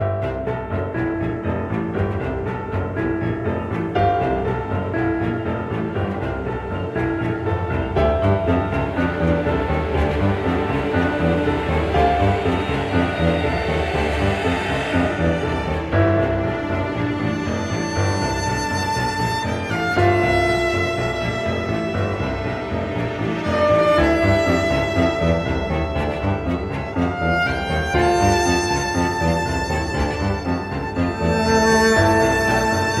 Description Phantom Aqua's Boss Theme
Source Ripped from the official soundtrack